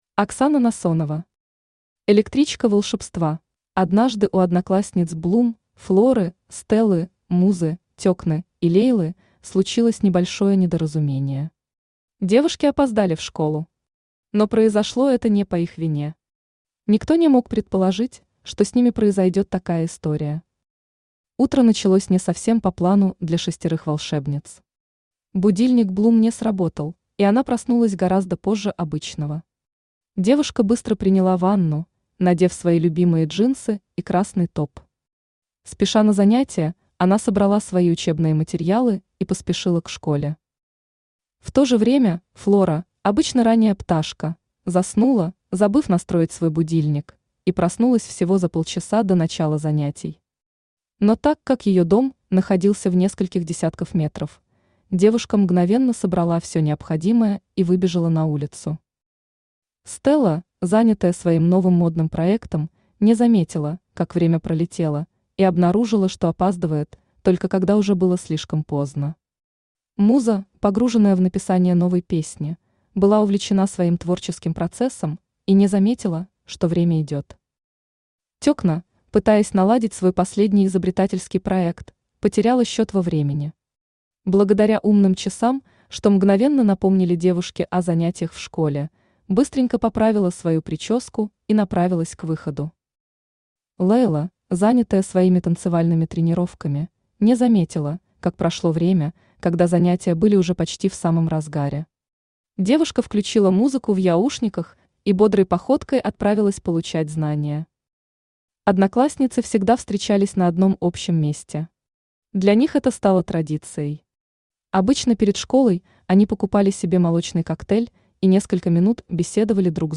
Аудиокнига Электричка волшебства | Библиотека аудиокниг
Aудиокнига Электричка волшебства Автор Оксана Александровна Насонова Читает аудиокнигу Авточтец ЛитРес.